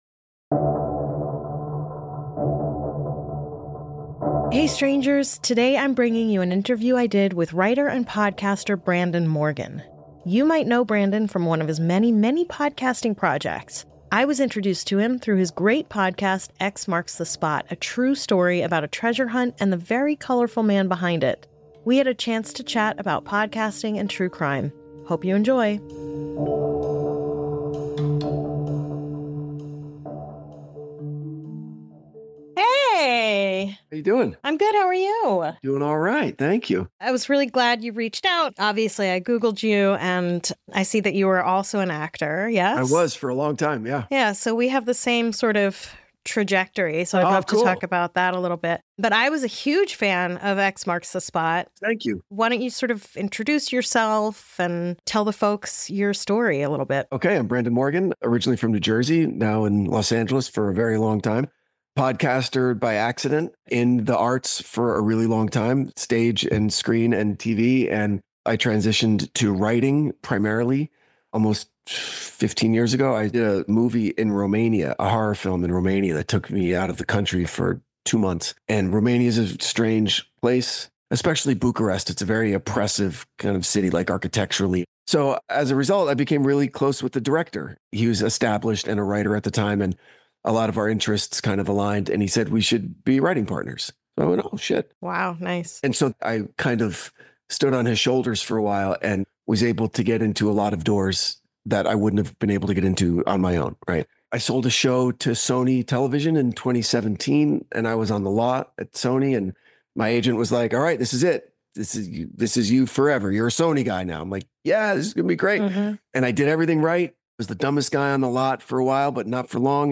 S4 Ep51: BONUS! Interview